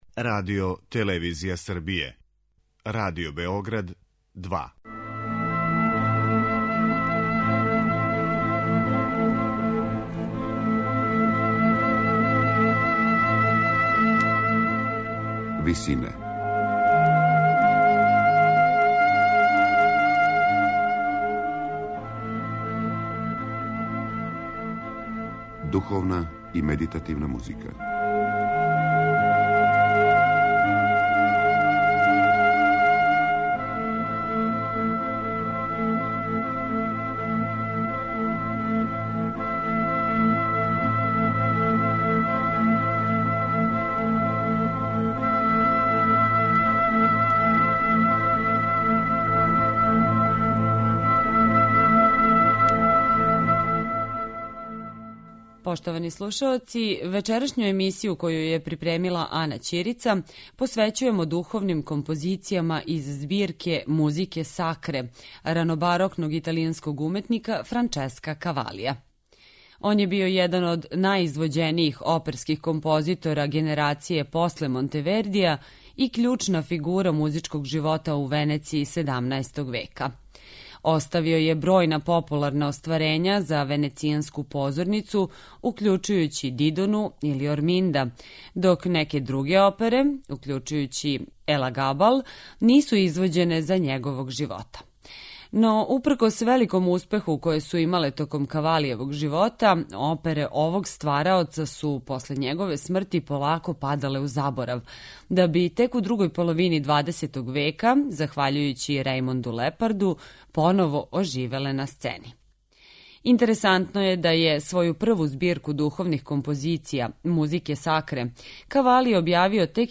Емисију духовне и медитативне музике посвећујемо првој збирци духовних композиција ранобарокног италијанског уметника Франческа Кавалија, која је објављена тек 1656.године, када је аутор већ увелико био на врхунцу оперске славе...
На крају програма, у ВИСИНАМА представљамо медитативне и духовне композиције аутора свих конфесија и епоха.